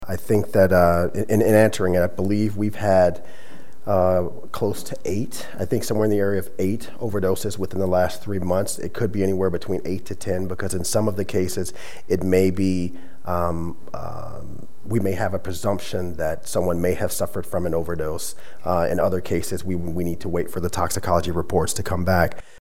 Director of RCPD Brian Peete spoke at Monday’s Law Board meeting.